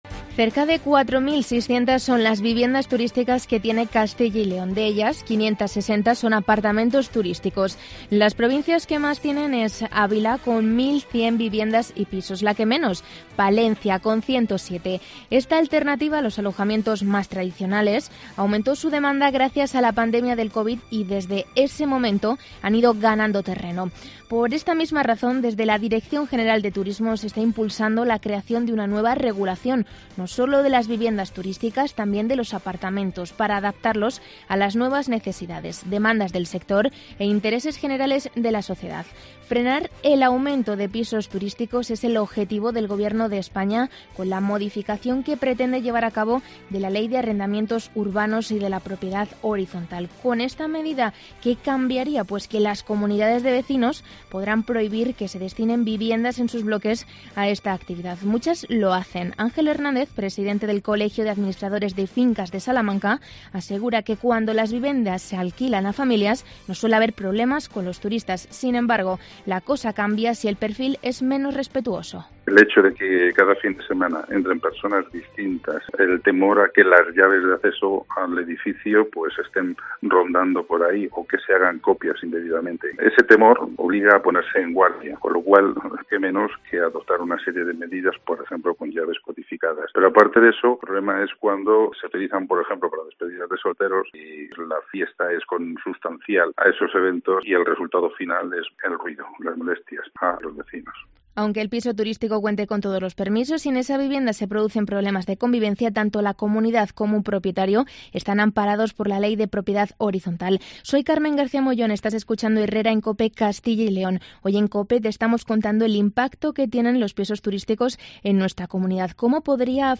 empresario con apartamentos turísticos comenta en COPE la nueva normativa